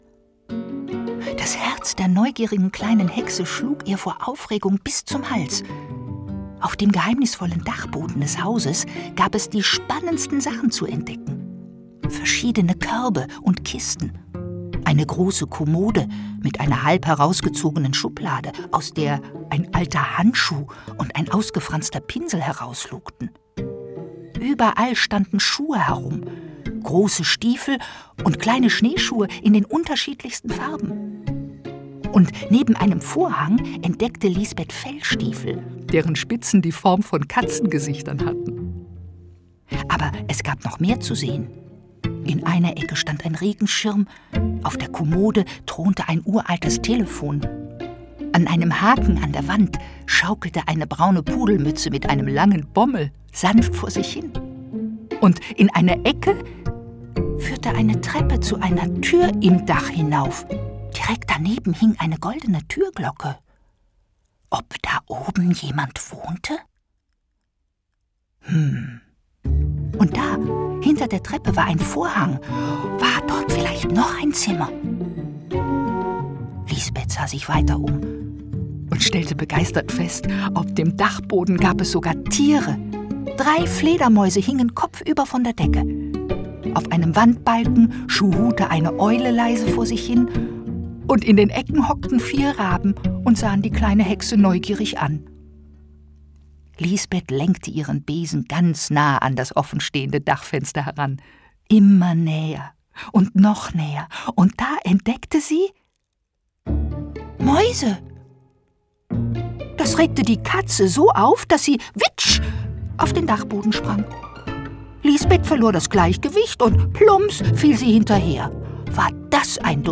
Ravensburger Die neugierige kleine Hexe ✔ tiptoi® Hörbuch ab 3 Jahren ✔ Jetzt online herunterladen!
Die_neugierige_kleine_Hexe-Hoerprobe.mp3